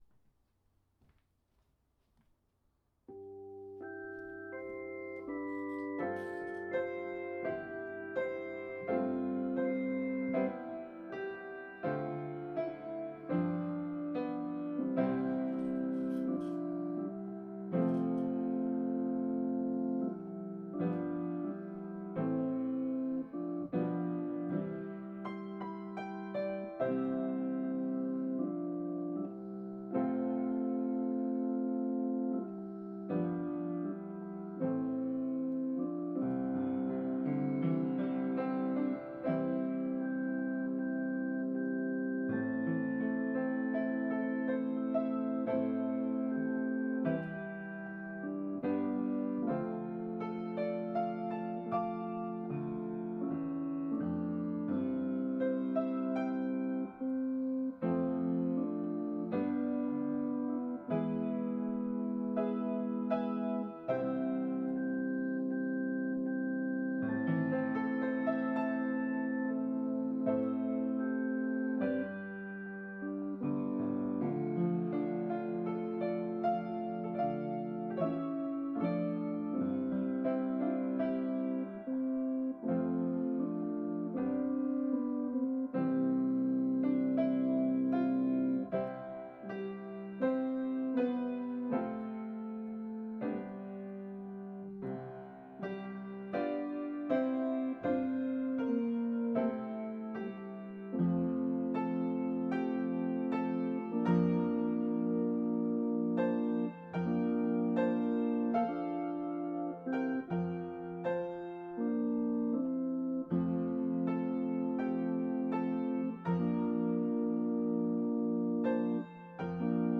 Voicing/Instrumentation: Organ/Organ Accompaniment